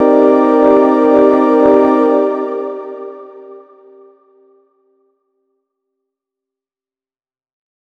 001_LOFI CHORDS MIN9_3.wav